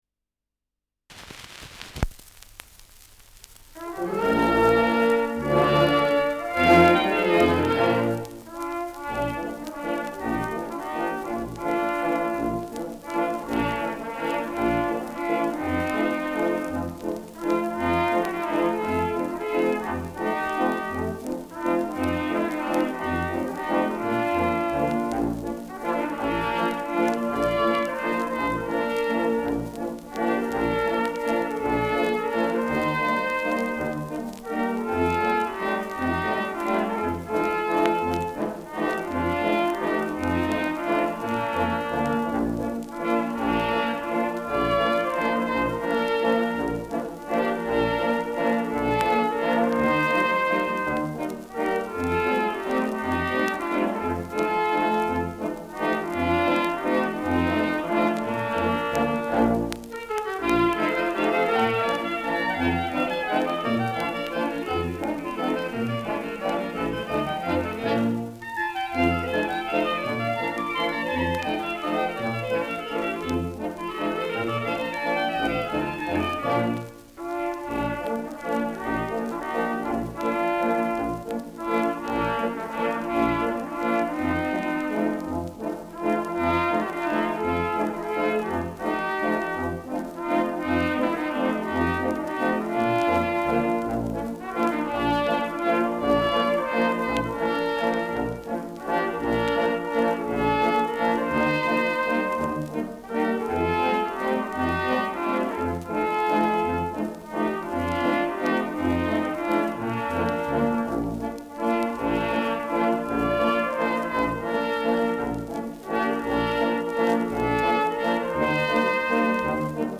Walzer
Schellackplatte
Leichtes Grundrauschen : Durchgehend leichtes Knacken